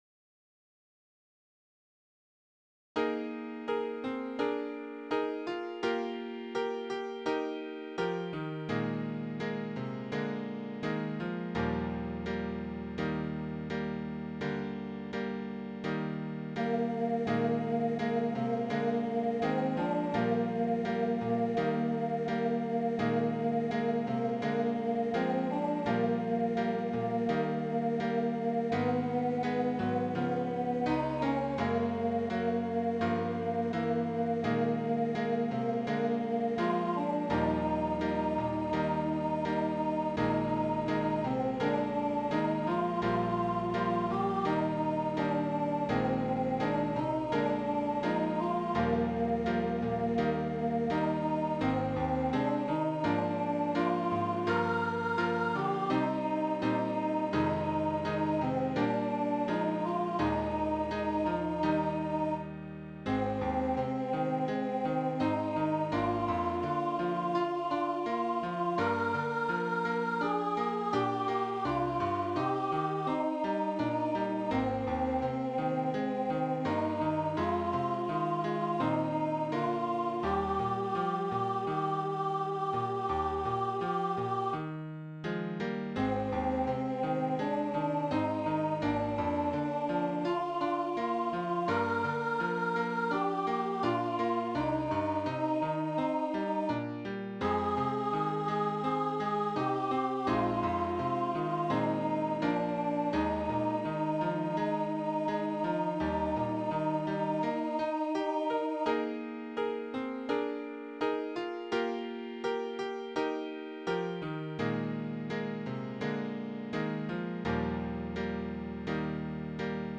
Feed My Lambs, Low solo in Eb
Vocal Solo Medium Voice/Low Voice